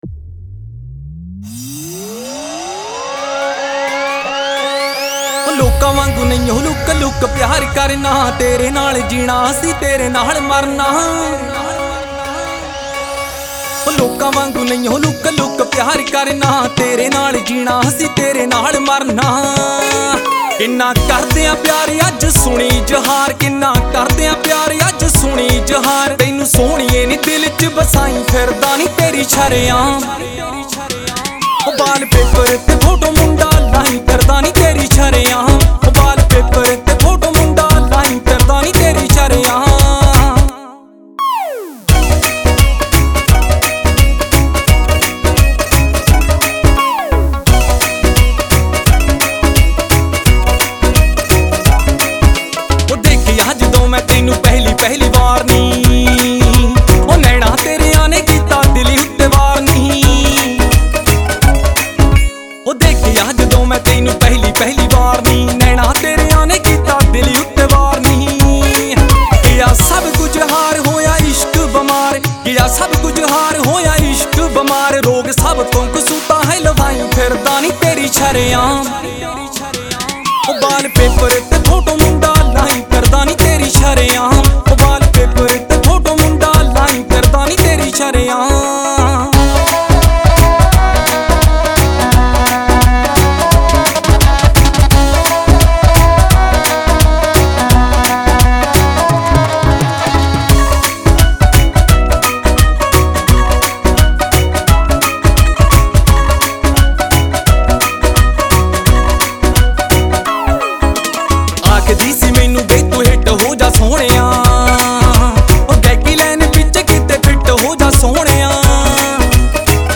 Punjabi Audio Songs